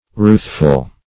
Ruthful \Ruth"ful\, a.